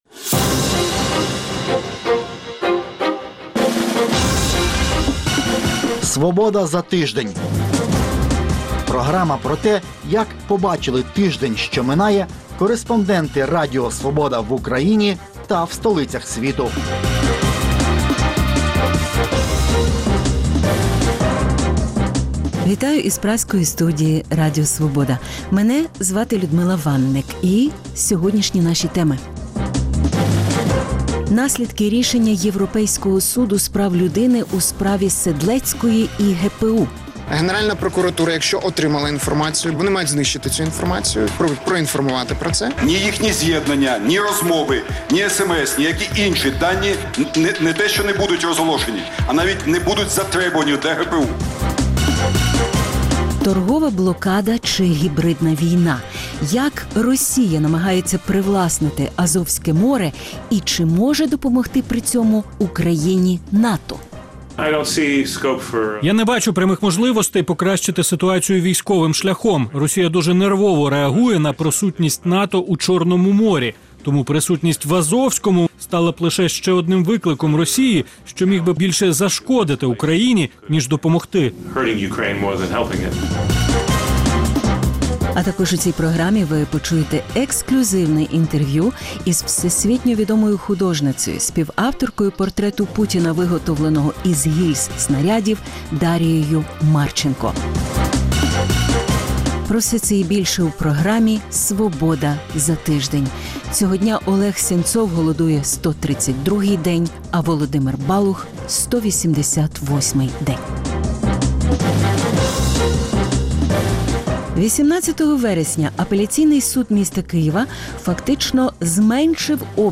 Наслідки рішення Європейського суду з прав людини у «справі Седлецької і ГПУ» Торговельна блокада чи гібридна війна? Як Росія намагається привласнити Азовське море? Ексклюзивне інтерв’ю із всесвітньо-відомою художницею